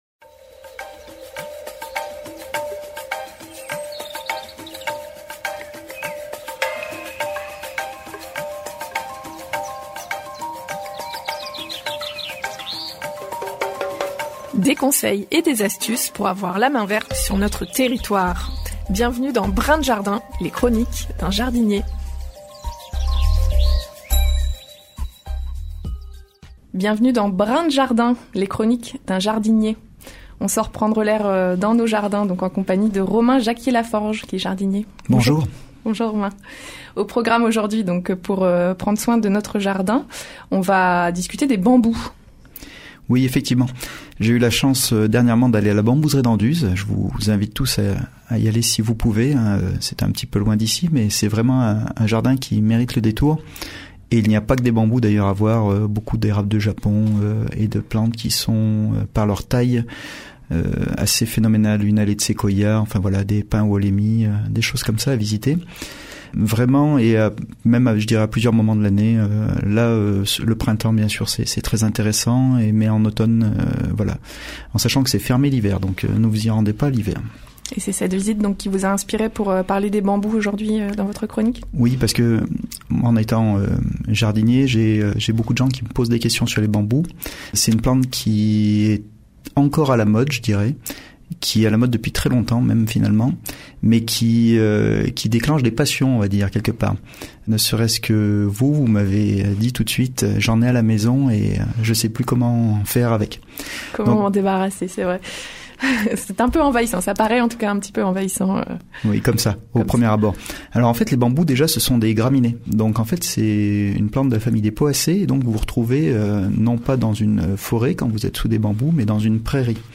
La nouvelle chronique hebdomadaire sur les ondes de Radio Royans Vercors